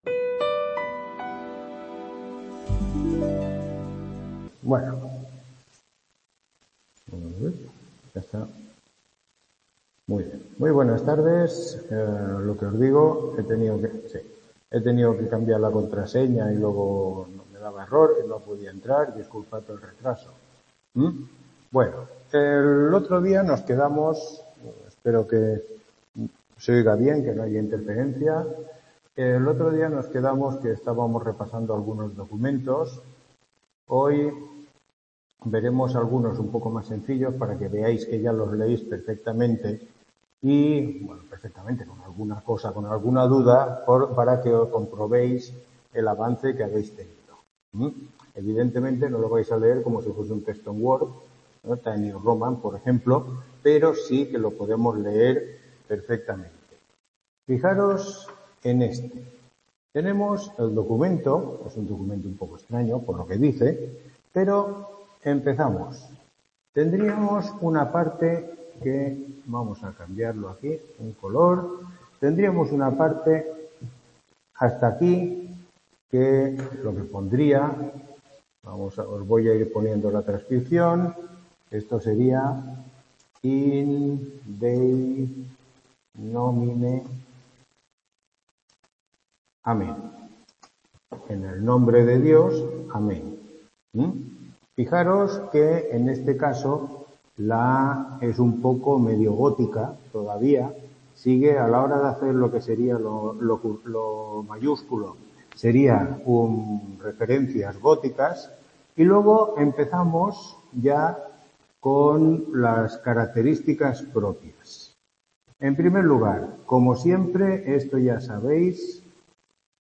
Tutoría 10